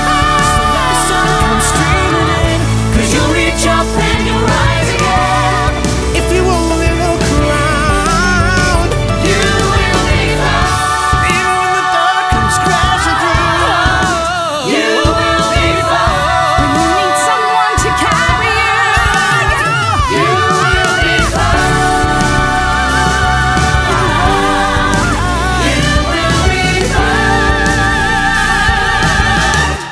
• Vocal